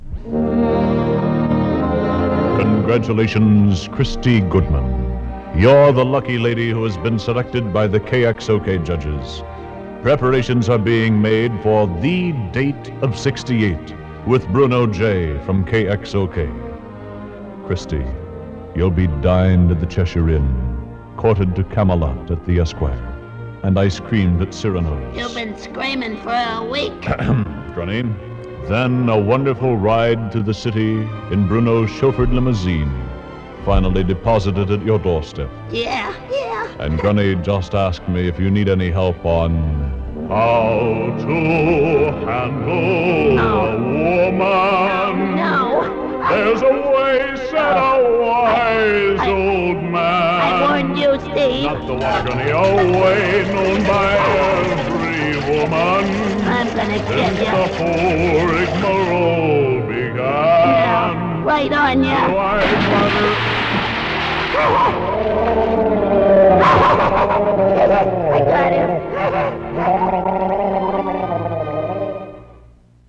radio promo